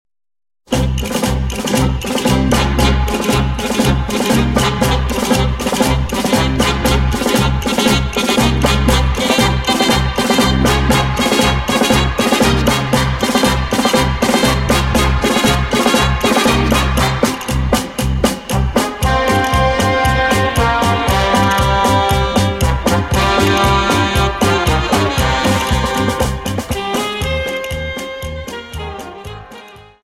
Dance: Paso Doble